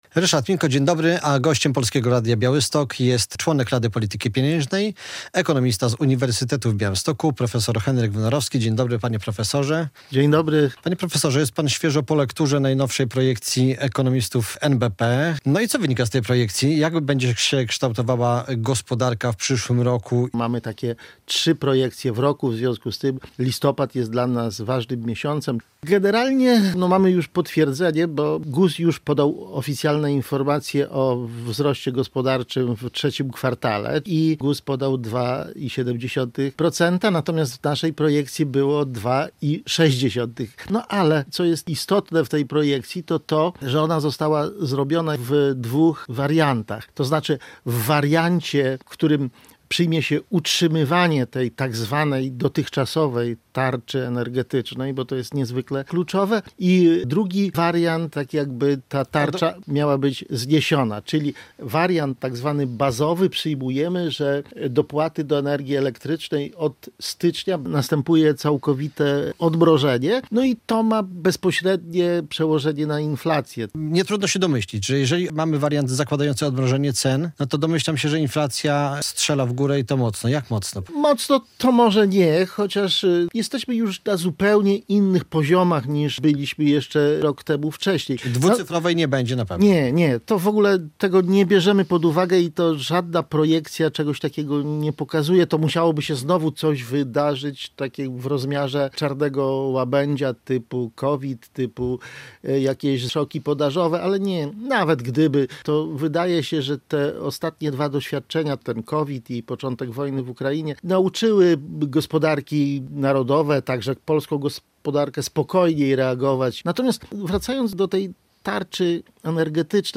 rozmawiał z członkiem Rady Polityki Pieniężnej, ekonomistą z Uniwersytetu w Białymstoku profesorem Henrykiem Wnorowskim.